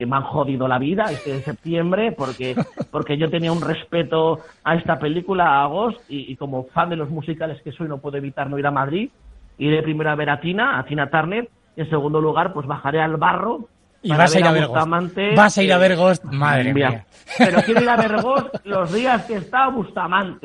crónica social de la semana